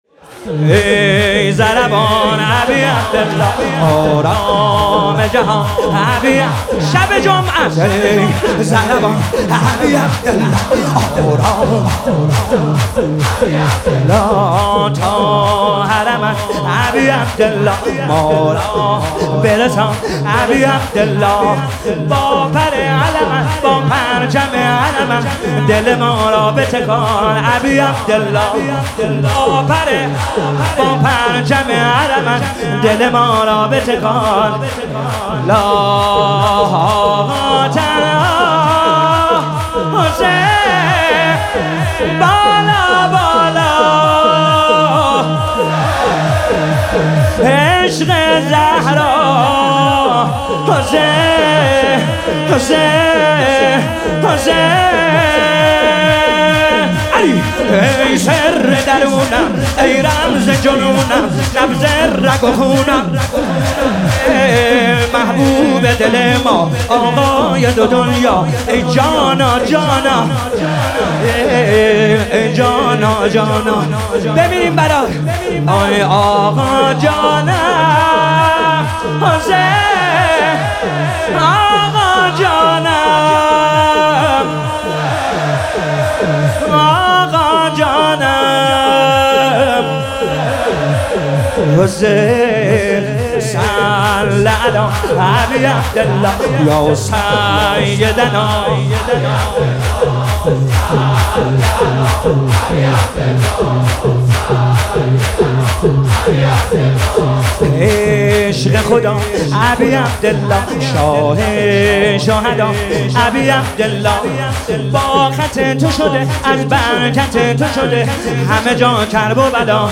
مداحی_شهادت حضرت زهرا